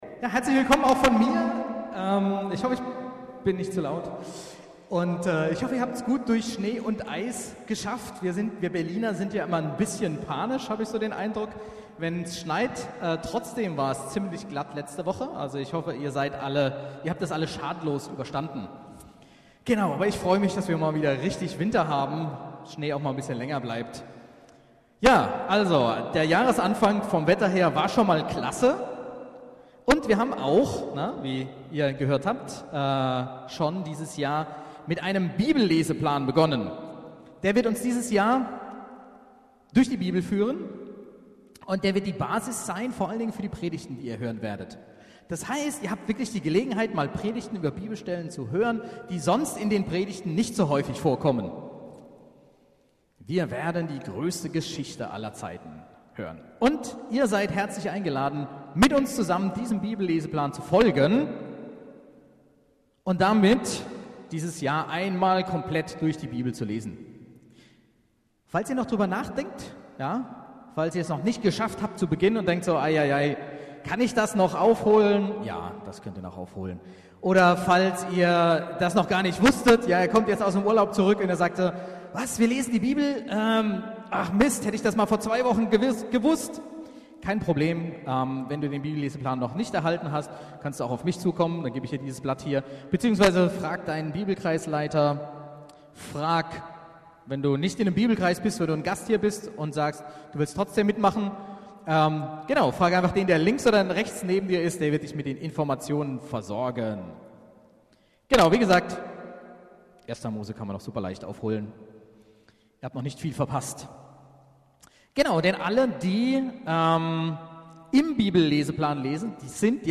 BGC Predigten Gottesdienst Podcast - Josef - Böses wurde durch Gott zu Gutem | Free Listening on Podbean App